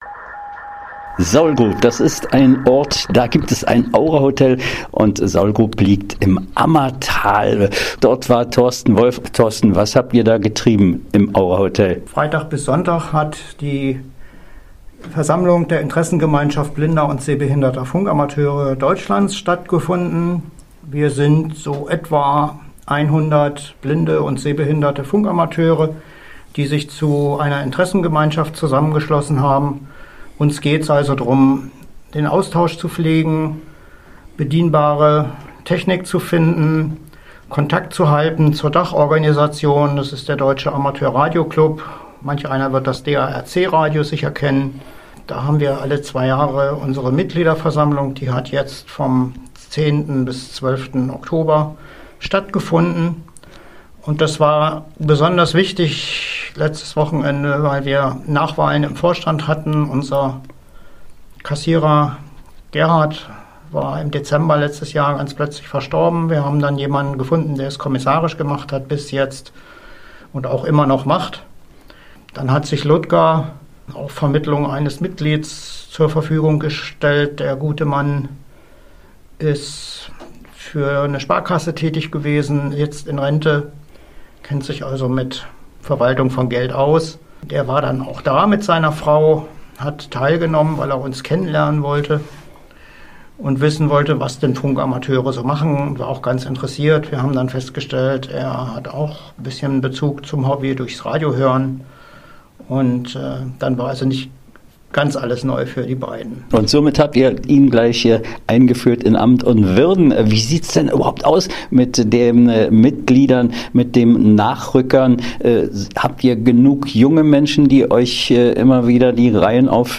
Interview 14.10.2025